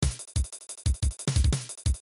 Mesure : 4/4
Tempo : 1/4=524
Instruction : channel 10
afrocuba.mp3